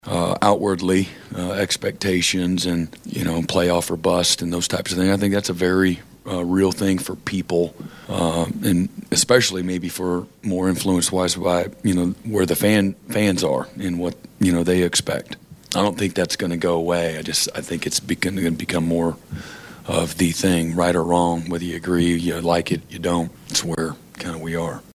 Head coach Brent Venables talks about that landscape.